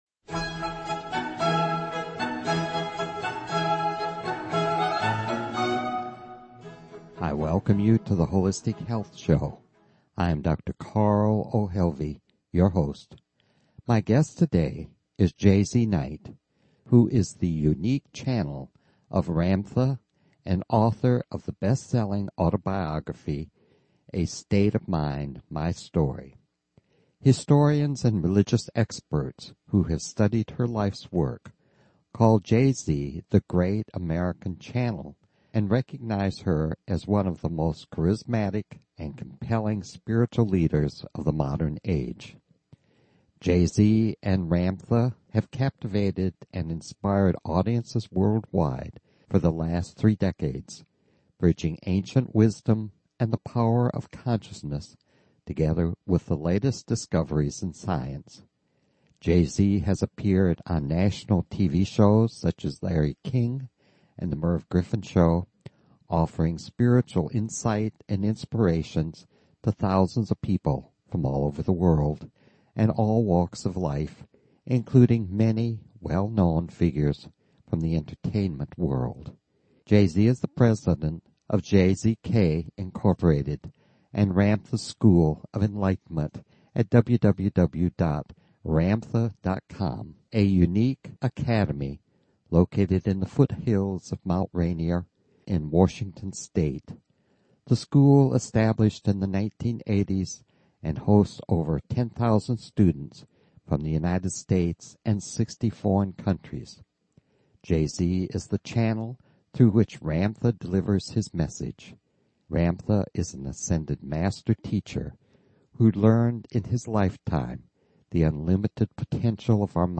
Talk Show Episode, Audio Podcast, The_Holistic_Health_Show and Courtesy of BBS Radio on , show guests , about , categorized as